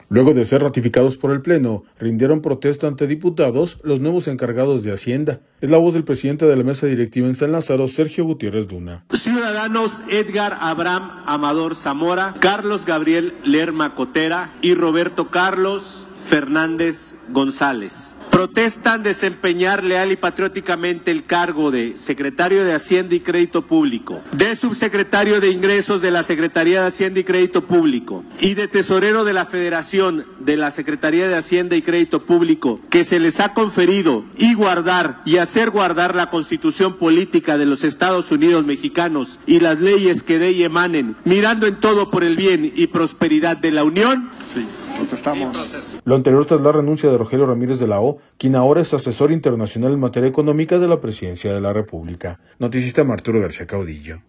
Luego de ser ratificados por el Pleno, rindieron protesta ante diputados, los nuevos encargados de Hacienda. Es la voz del presidente de la Mesa Directiva en San Lázaro, Sergio Gutiérrez Luna.